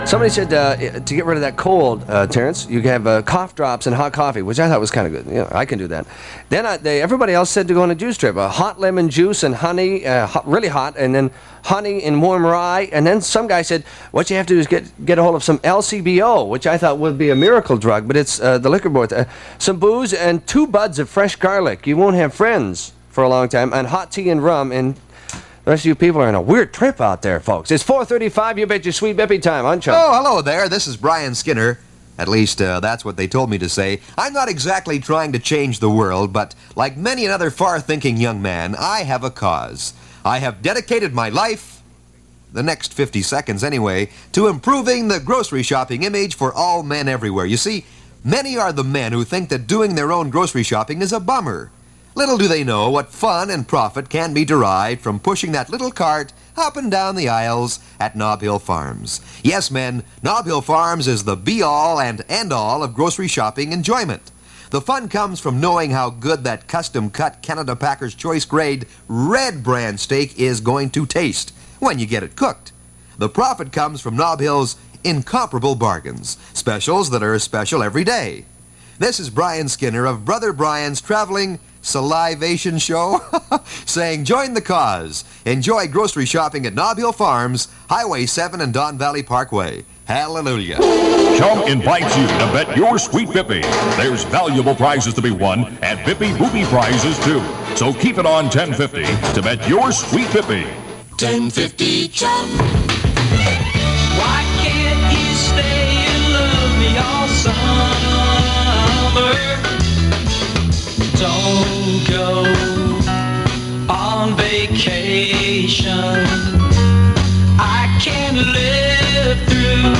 AIRCHECK OF THE WEEK
Enjoy this rare aircheck of Terry David Mulligan (UNSCOPED) on CHUM